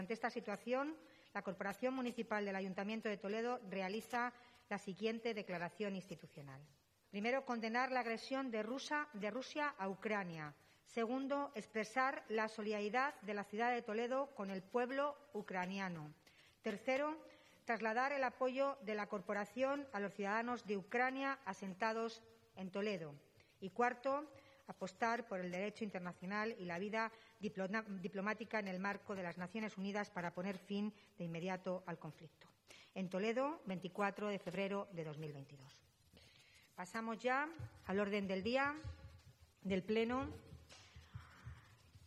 El Pleno municipal del Ayuntamiento de Toledo ha aprobado una declaración institucional de condena a la agresión que ha tenido lugar en la madrugada de este jueves 24 de febrero de Rusia a Ucrania.
La alcaldesa de Toledo, Milagros Tolón, ha dado lectura al texto que ha sido consensuado entre todos los grupos y miembros de la Corporación municipal y que incluye un total de cuatro puntos.